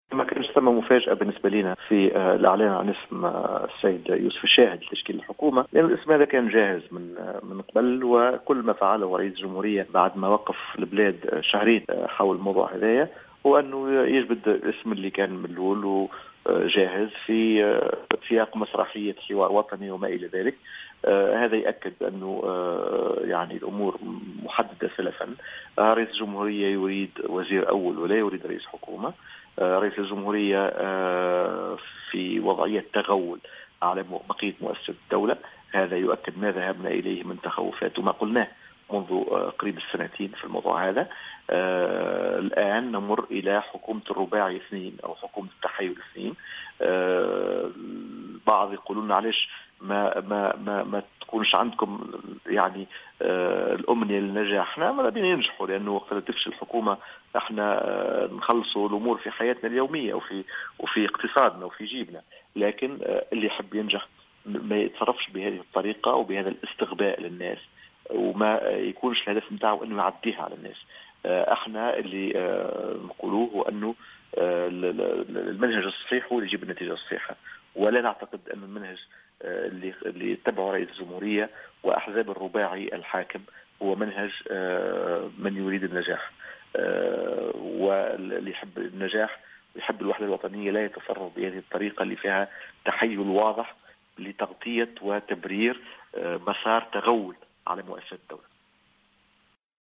قال عدنان منصر، أمين عام حزب تونس الإرادة في تصريح للجوهرة "اف ام" اليوم الأربعاء إن تعيين يوسف الشاهد رئيسا للحكومة لم يكن مفاجأة معتبرا أن هذا الإسم كان جاهزا من قبل وبعد أن قام رئيس الجمهورية بإيقاف البلاد لمدة شهرين حول هذا الموضوع أخرج هذا الإسم في سياق مسرحية حوار وطني .